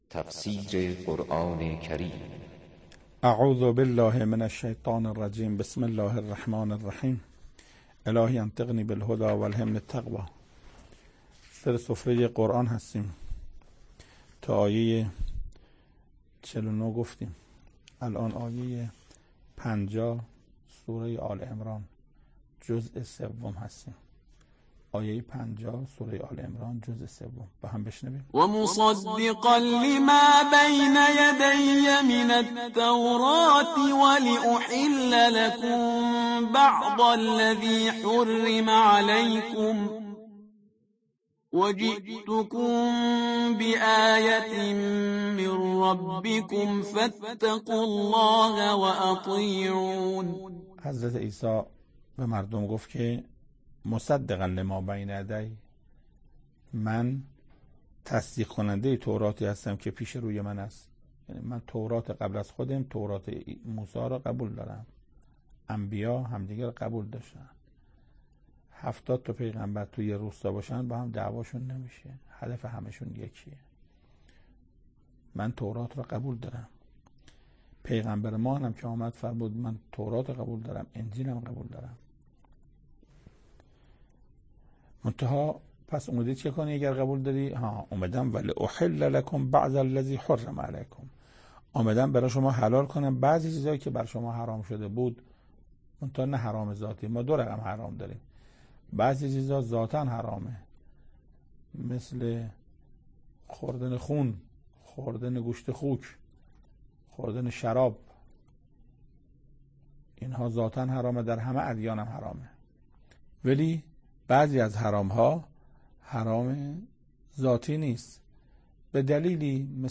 تفسیر پنجاهمین آیه از سوره مبارکه آل عمران توسط حجت الاسلام استاد محسن قرائتی به مدت 8 دقیقه
سخنرانی محسن قرائتی